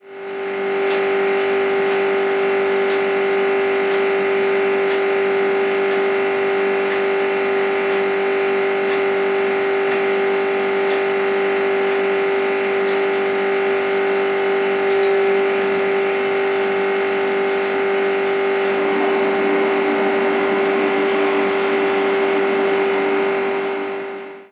Motor de una nevera